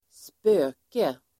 Uttal: [²sp'ö:ke]